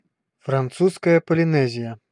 Ääntäminen
IPA : /ˈfrɛntʃ pɒl.ɪˈniː.ʒə/